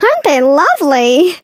flea_ulti_vo_02.ogg